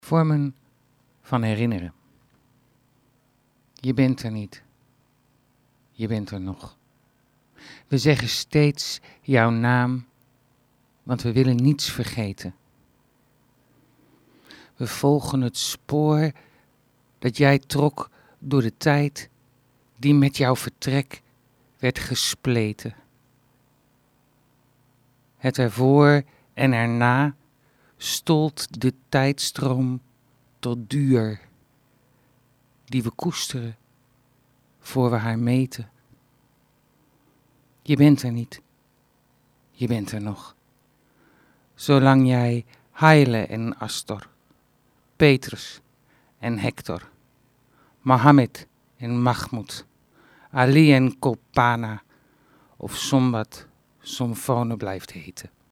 gedicht